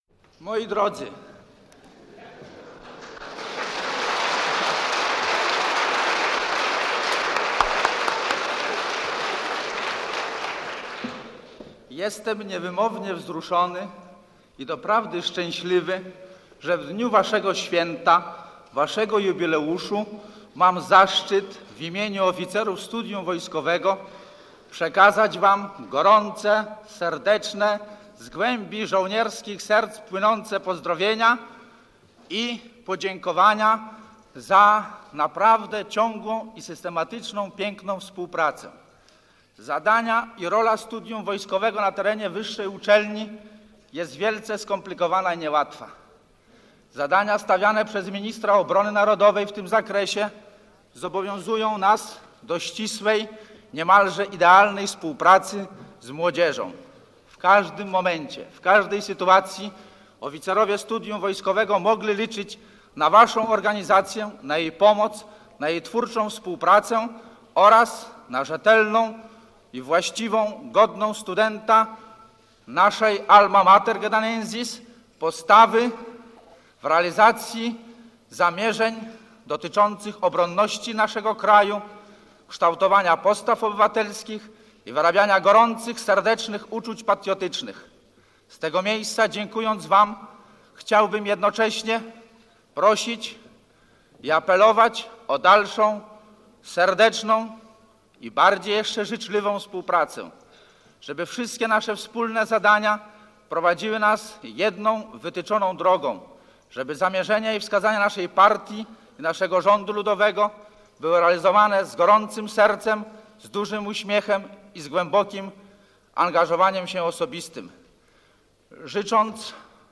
Jubileuszowa sesja Parlamentu PG cd.: relacja [dokument dźwiękowy] - Pomorska Biblioteka Cyfrowa